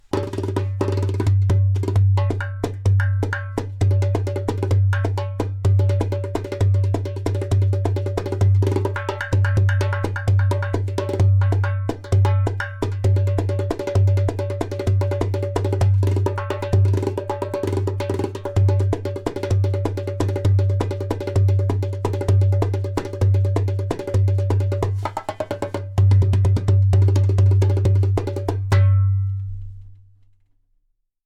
P r e m i u m Line Darbuka
In this exclusive line, materials such as clay, glaze, and natural goat skin come together in a magical harmony, giving life to a balanced, resonant sound.
• High sound clear “taks”.
• Deep bass
• Very strong clay “kik”/click sound